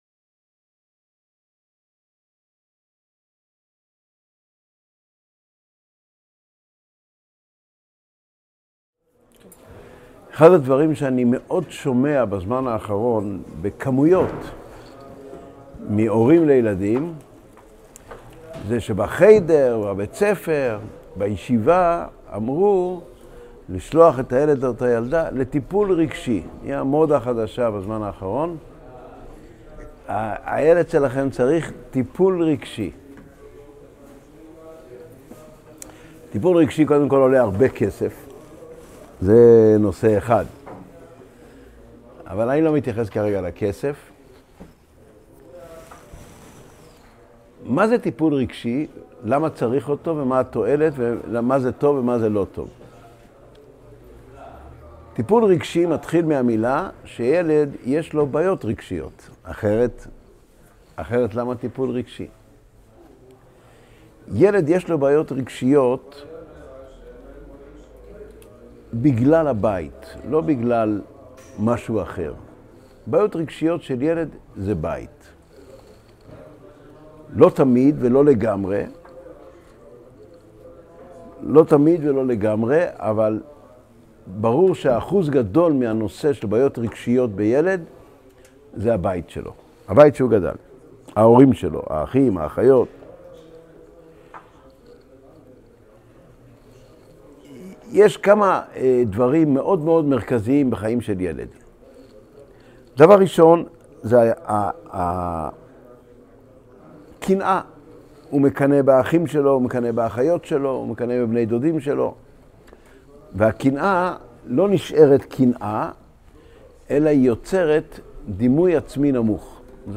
Содержание урока: Зависть порождает низкую самооценку. Имеет ли человек право на ошибку? Проблемы дислекции.